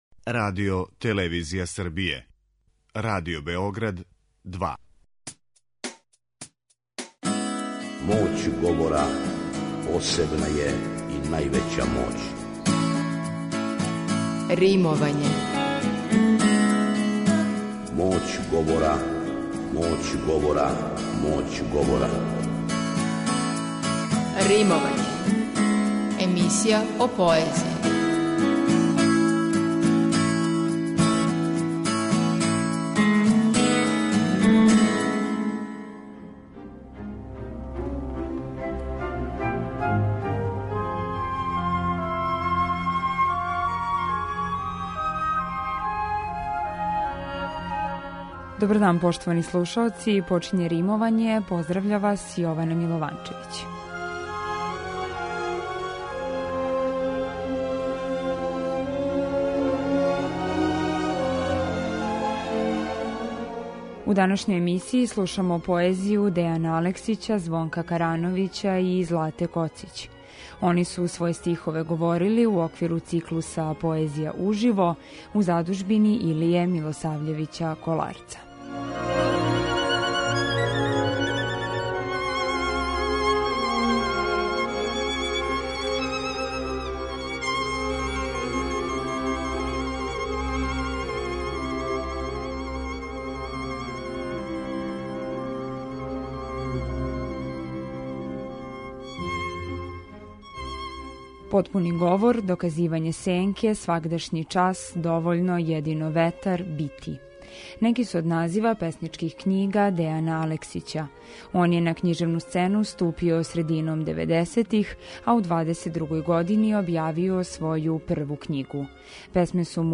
Они су своје стихове говорили у оквиру програма ПОЕЗИЈА УЖИВО у Малој сали Коларчеве задужбине.